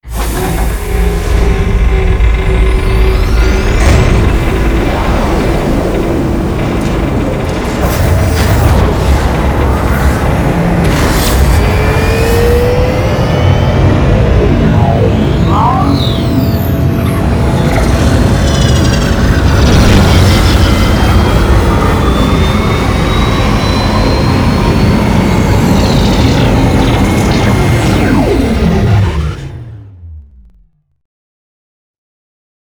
stargate.wav